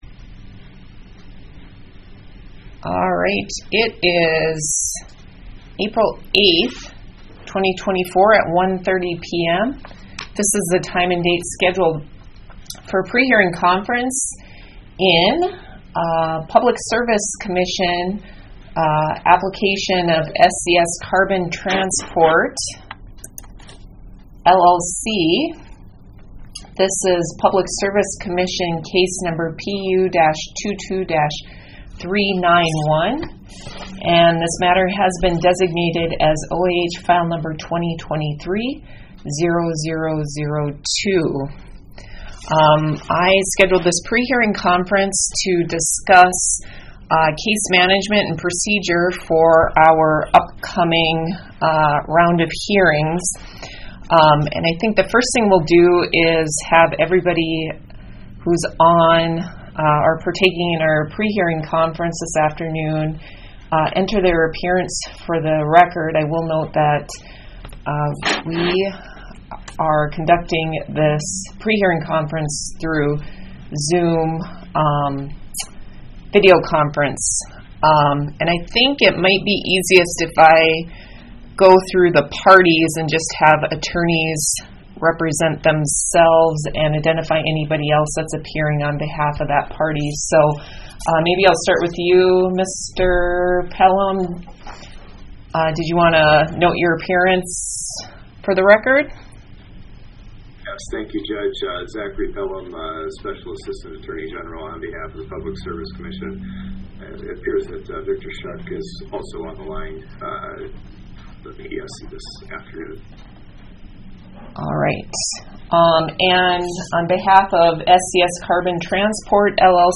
Electronic Recording of 8 April 2024 Prehearing Conference